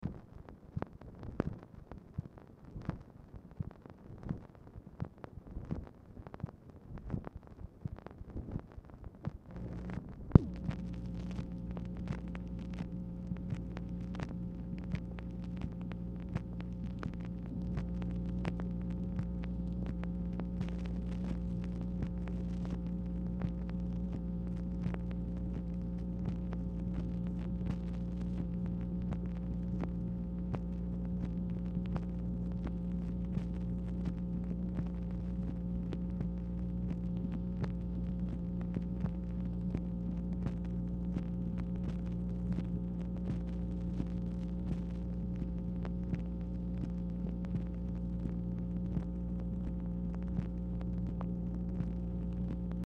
Telephone conversation # 10775, sound recording, MACHINE NOISE, 9/14/1966, time unknown | Discover LBJ
Format Dictation belt
Specific Item Type Telephone conversation